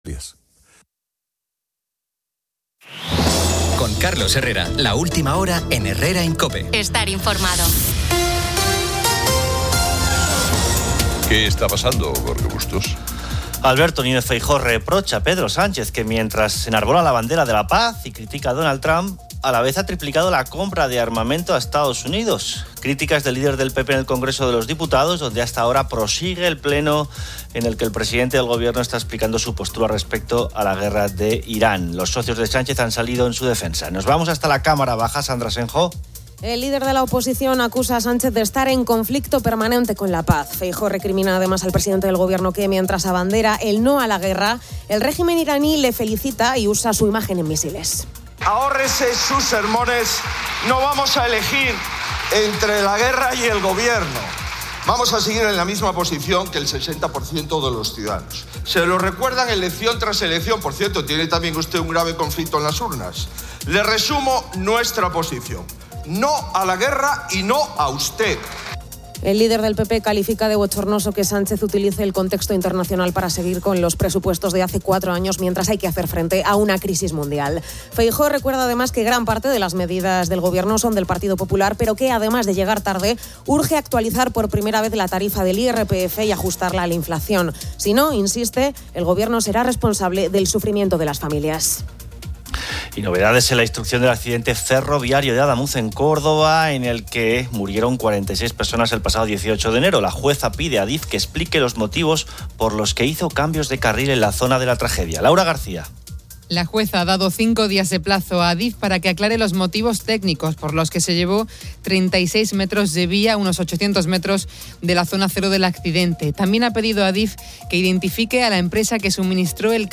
Andrés Roca Rey, en entrevista, analiza la próxima temporada taurina en Sevilla, la evolución del toreo y la importancia de integrar a la juventud.